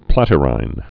(plătĭ-rīn)